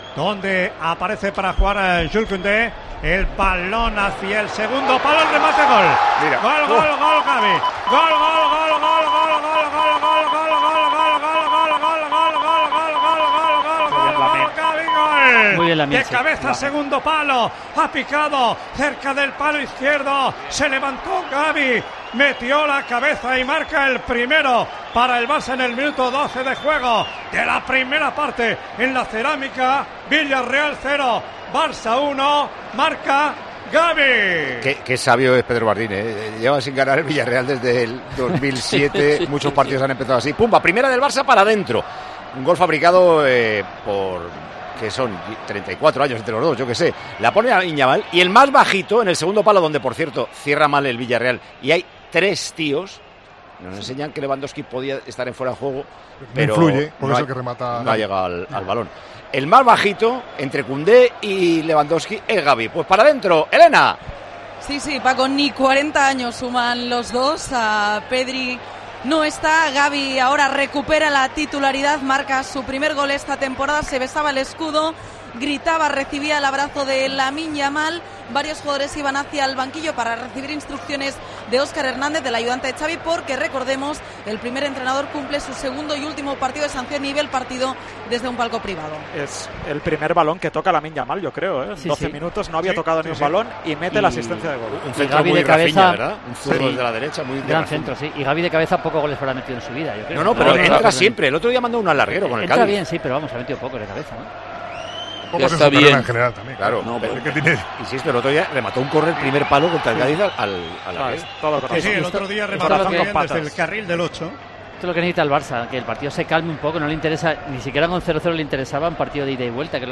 Así se narró la asistencia de Yamal y el gol de Gavi: